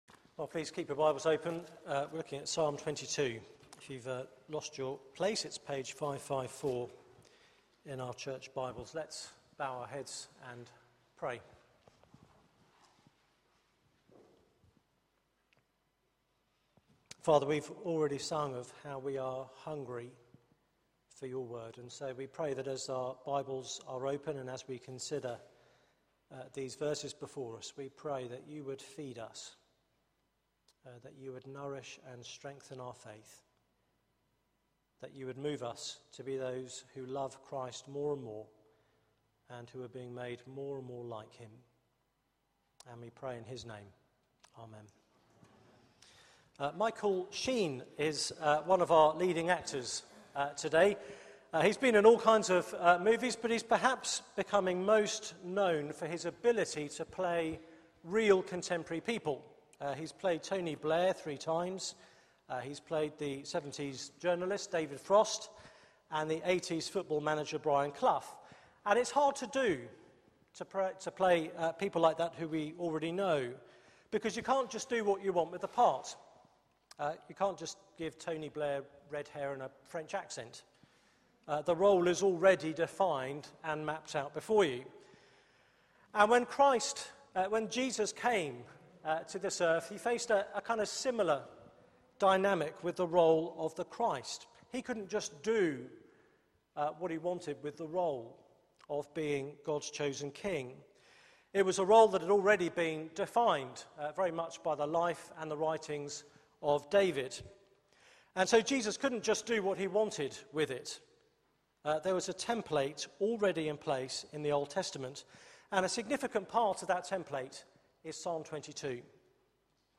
Media for 6:30pm Service on Sun 25th Aug 2013 18:30 Speaker
Summer Songs Theme: The God-forsaken God Sermon Search the media library There are recordings here going back several years.